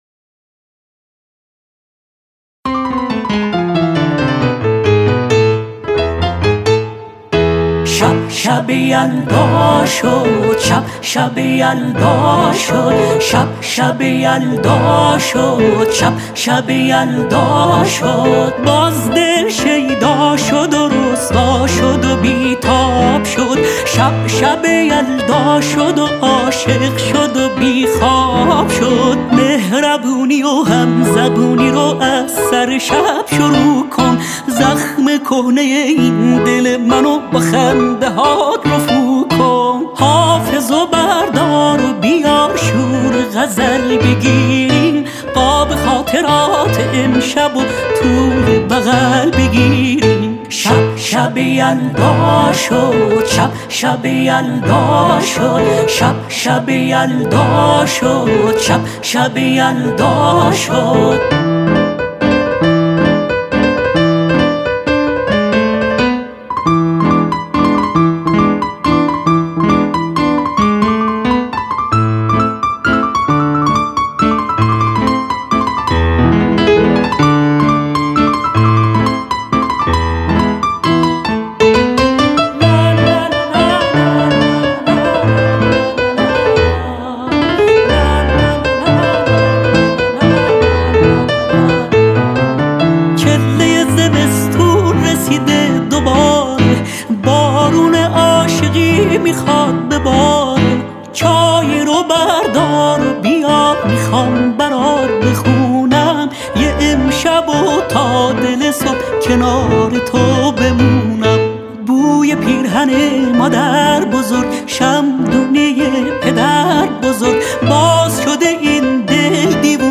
صدای دلنشین